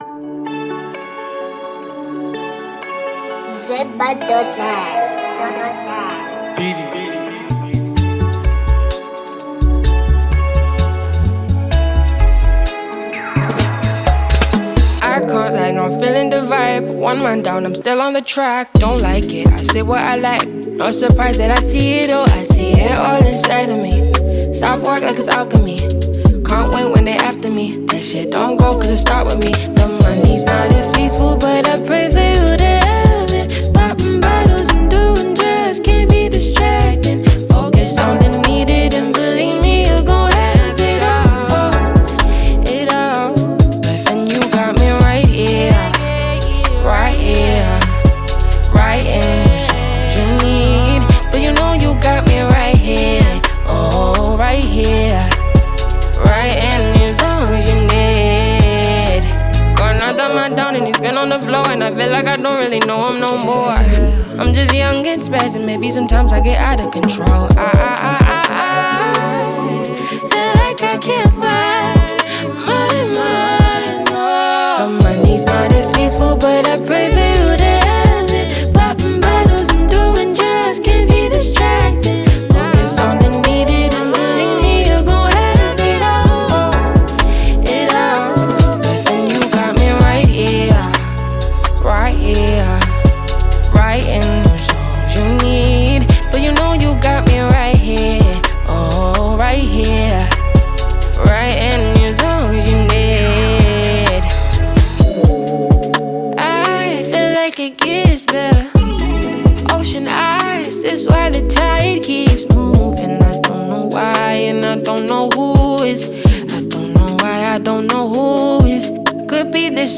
Genre: Afrobeat Year